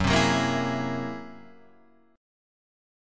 F#9b5 Chord